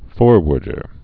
(fôrwər-dər)